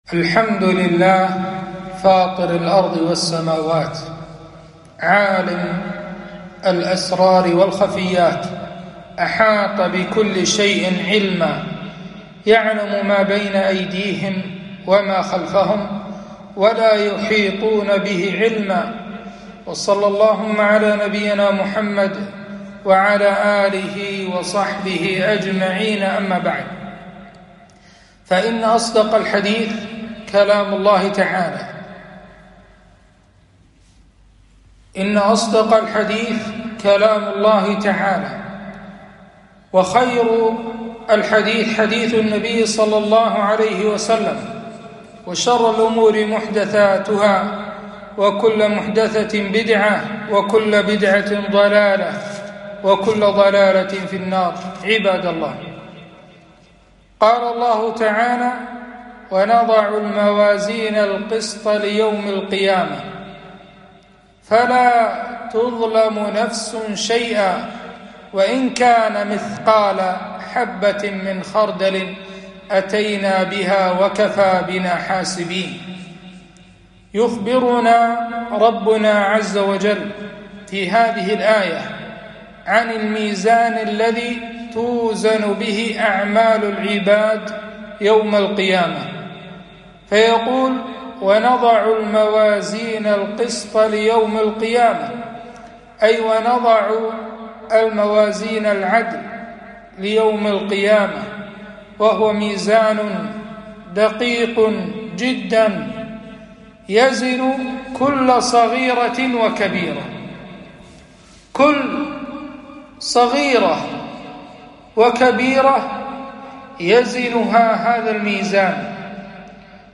خطبة - وزن الأعمال يوم القيامة